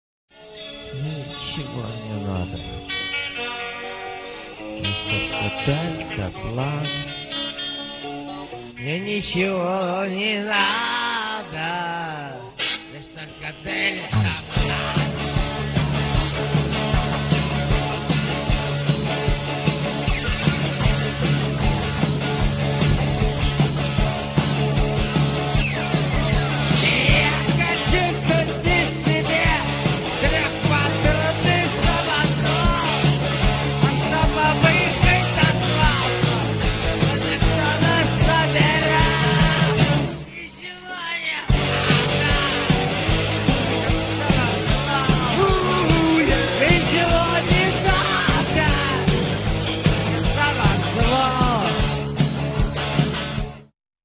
фрагмент песни ( 55 сек.)